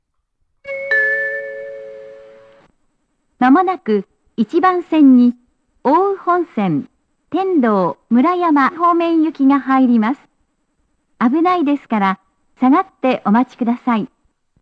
天童･村山方面接近放送
●上りが男声、下りが女声の、路線名まで言ってくれる細かいアナウンスです。
●接近チャイムは仙台駅仙石線と同じタイプ。
●1番線下りは何故か1回しか流れません。
●スピーカー：ユニペックス小型